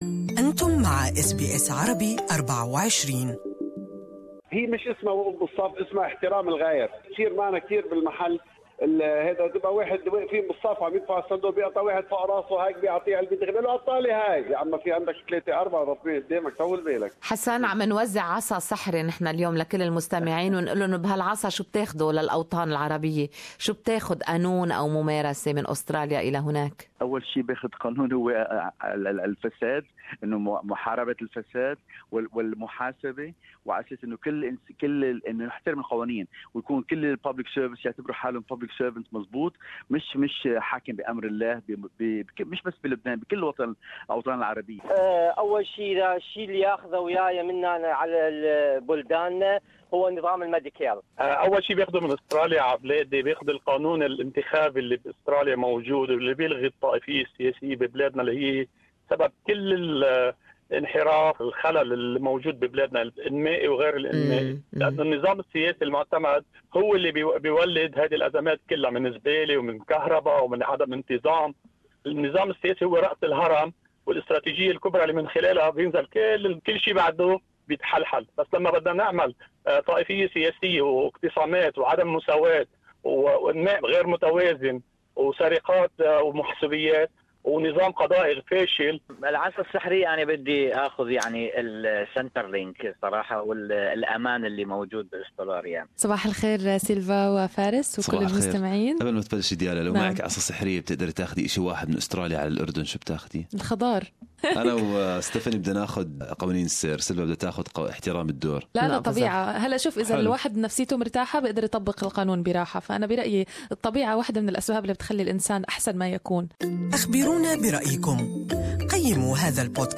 GMA talkback this morning asked callers about one thing they'd take to their home countries from Australia.